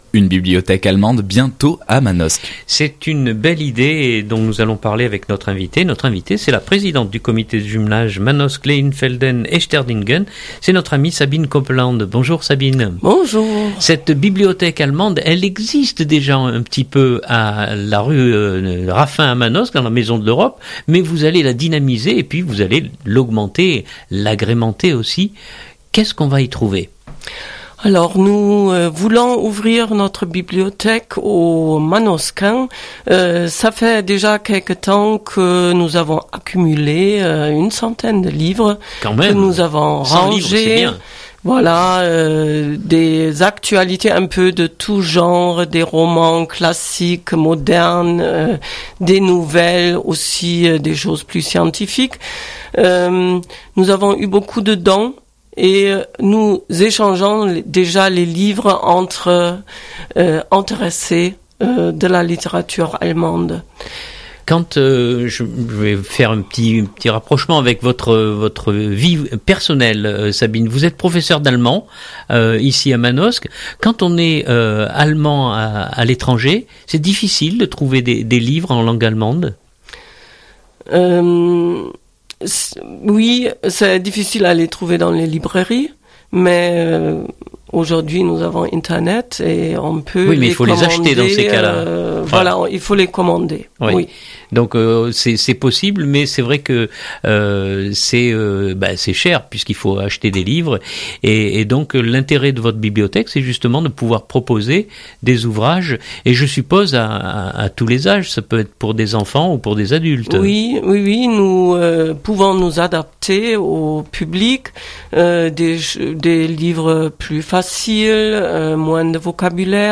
Elle témoigne à propos de l’ouverture d’une bibliothèque allemande dans la Cité de Giono et répond aux questions